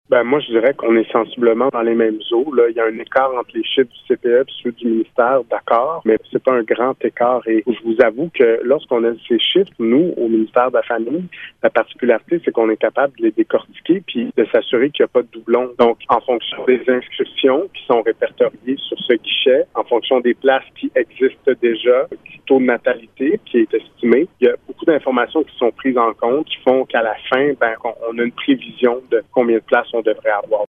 Le ministre de la Famille et ministre responsable de l’Outaouais, Mathieu Lacombe, comprend que les chiffres peuvent sembler inexacts.